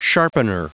Prononciation du mot sharpener en anglais (fichier audio)
Prononciation du mot : sharpener